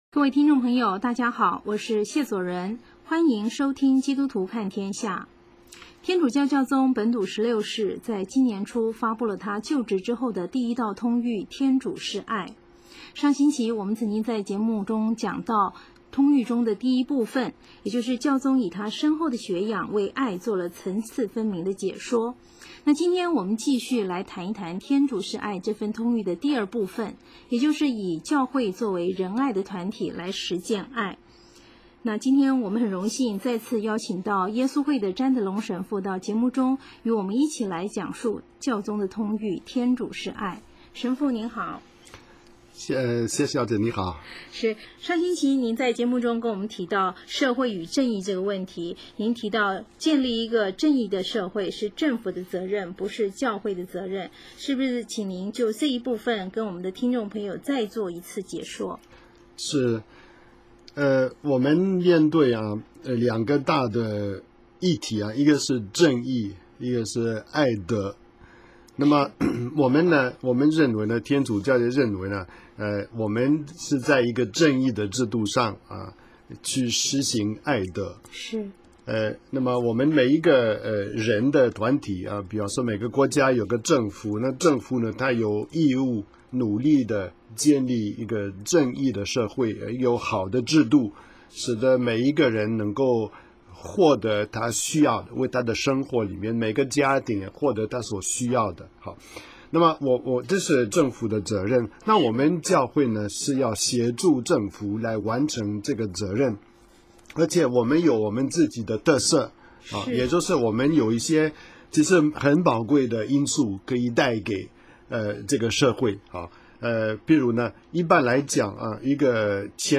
耶稣会士